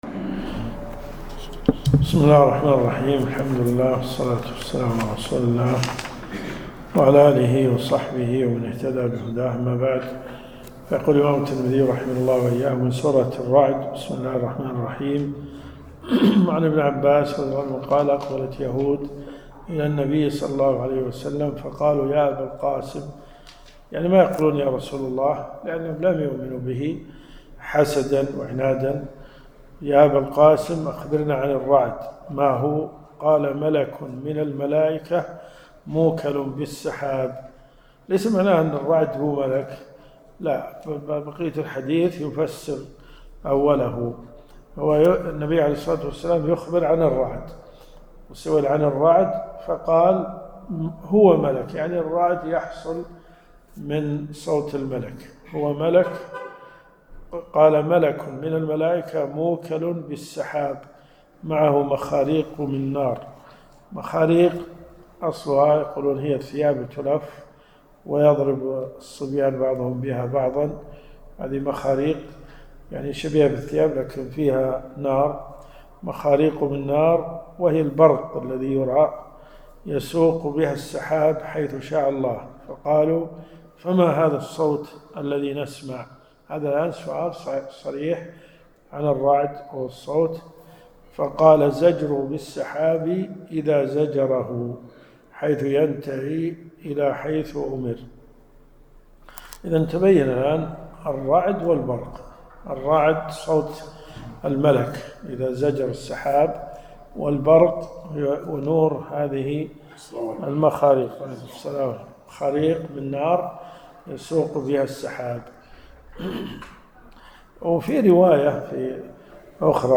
الرئيسية الكتب المسموعة [ قسم الحديث ] > جامع الترمذي .